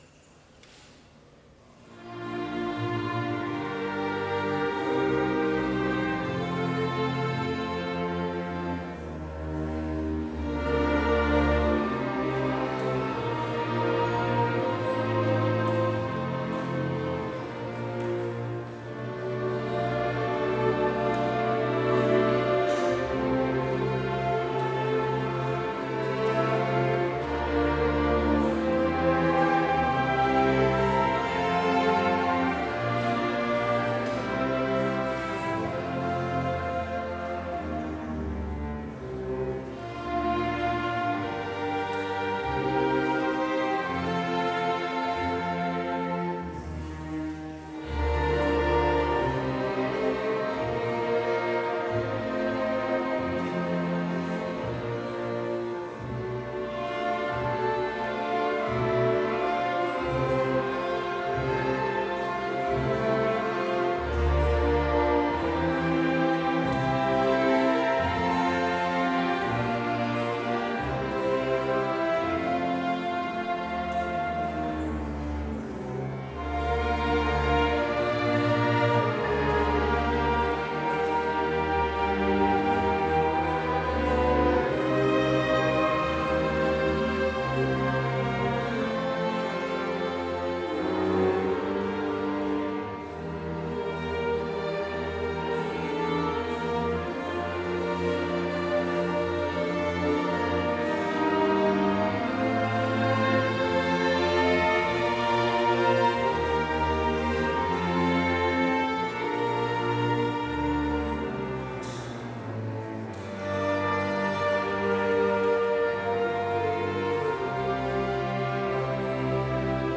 Complete service audio for Chapel - October 1, 2021
Order of Service Prelude